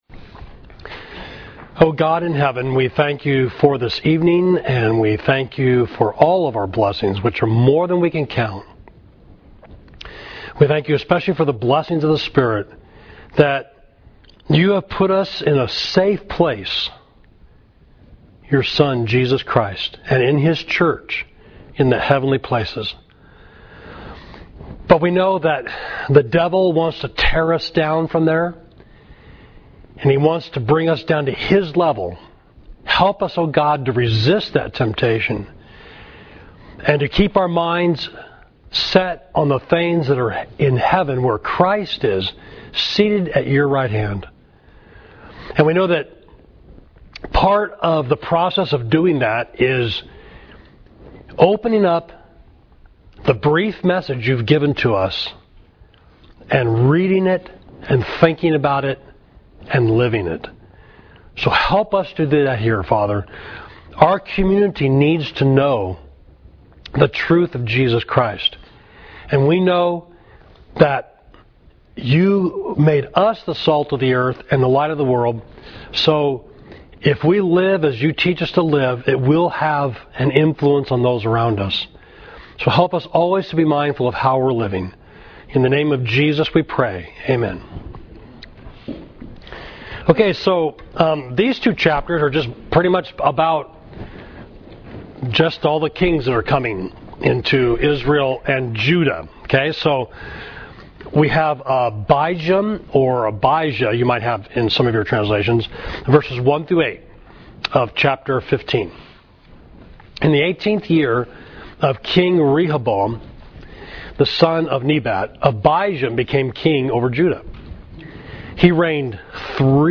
Class: Loyalty Versus Disloyalty, 1 Kings 15 and 16